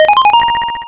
RedCoin3.wav